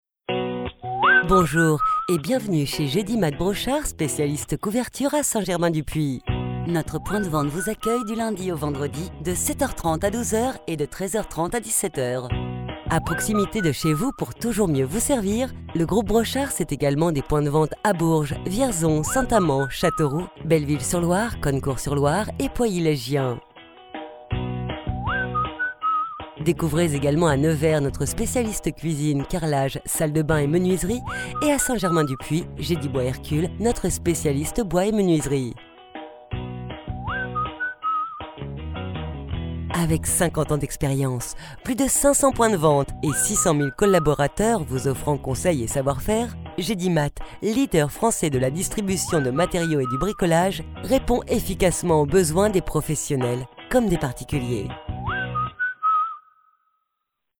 Natürlich, Zuverlässig, Erwachsene, Freundlich
Telefonie
She works from her personal studio so that your projects are recorded in the best possible quality.